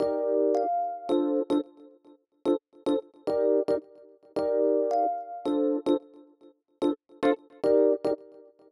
30 ElPiano PT1.wav